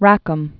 (răkəm), Arthur 1867-1939.